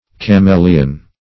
Cameleon \Ca*me"le*on\, n.